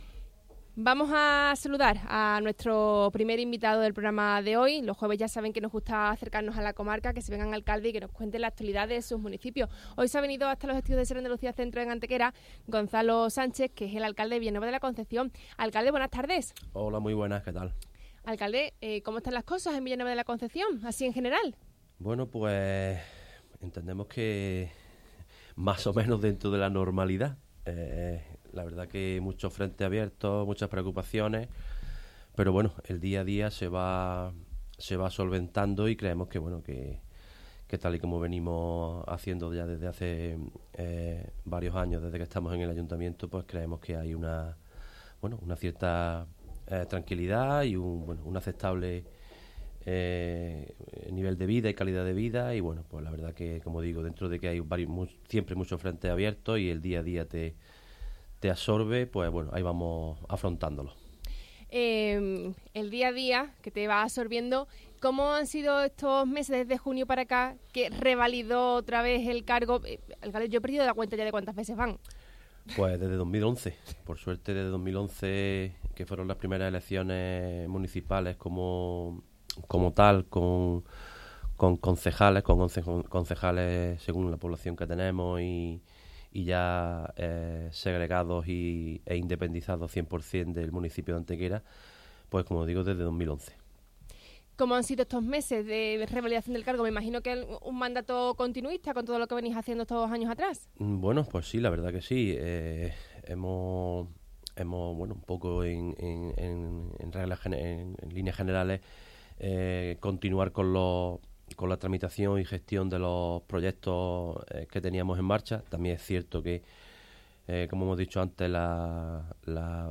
Entrevista Gonzalo Sánchez, alcalde Villanueva de la Concepción.